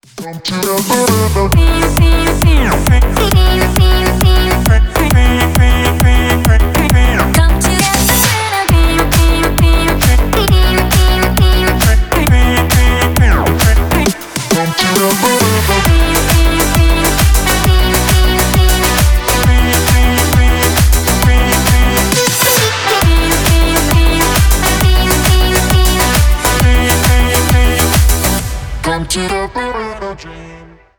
Танцевальные # без слов # весёлые